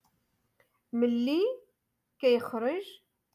Moroccan Dialect-Rotation five-Lesson Sixty